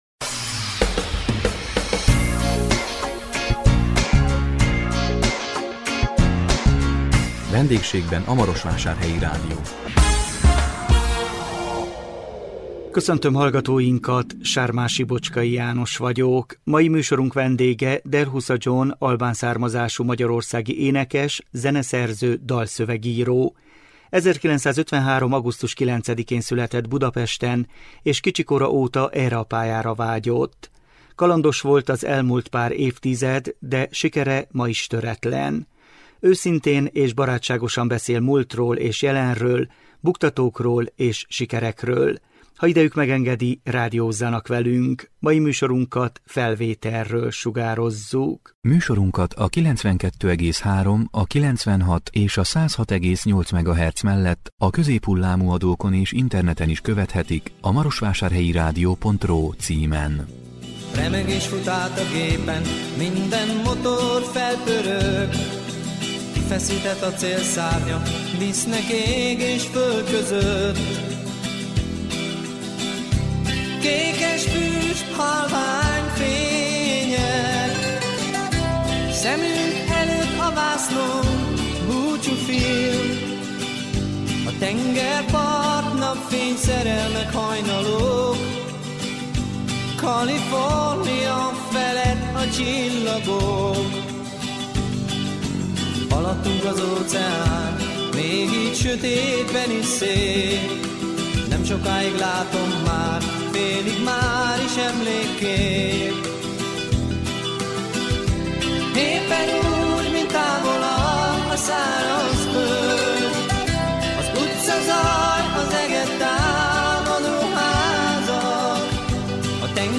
A 2024 október 17-én közvetített VENDÉGSÉGBEN A MAROSVÁSÁRHELYI RÁDIÓ című műsorunk vendége Delhusa Gjon albán származású magyarországi énekes, zeneszerző, dalszövegíró. 1953 augusztus 9-én született Budapesten és kicsi kora óta erre a pályára vágyott.
Őszintén és barátságosan beszél múltról és jelenről, buktatókról és sikerekről.